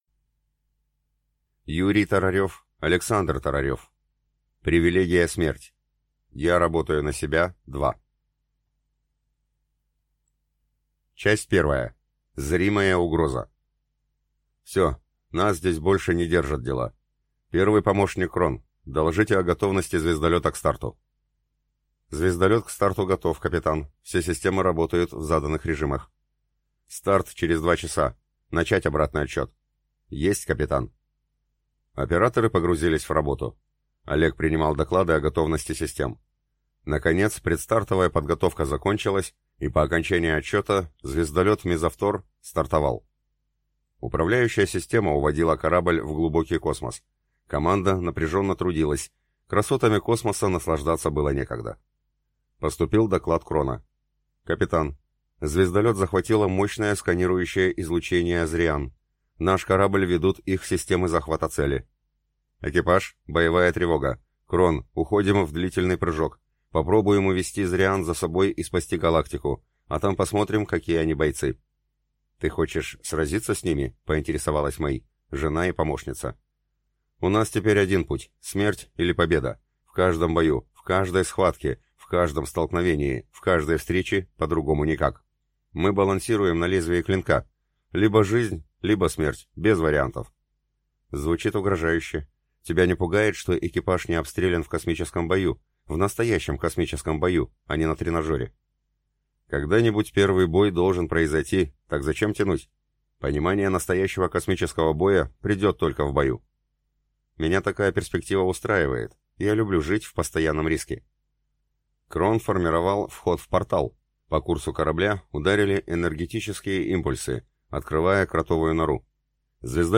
Аудиокнига Привилегия смерть | Библиотека аудиокниг